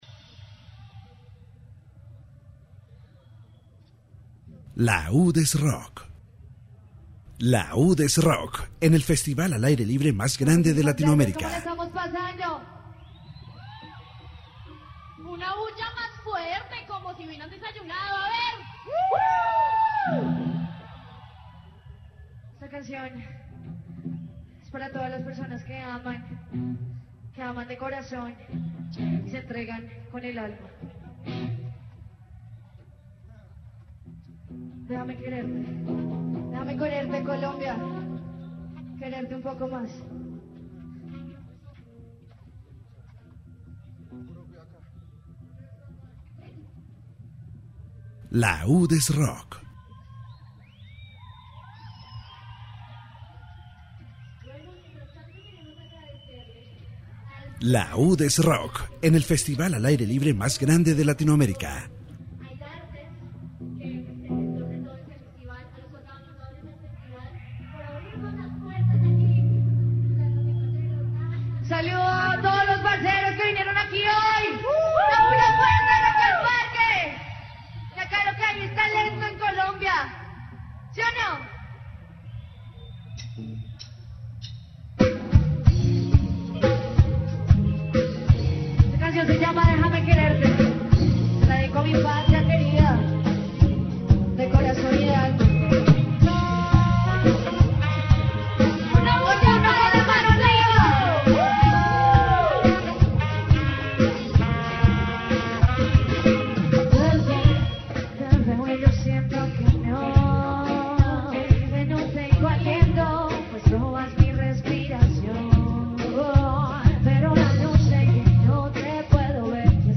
Radio report from Rock in the Park 2017 highlighting performances by local bands such as Valentine and La Vodka Nera. It discusses the role of the "clans" as training spaces for young musicians and the challenges faced by Bogotá bands to participate in the festival. Members of Valentine share their experiences in the clans, their path as a band, and future projects.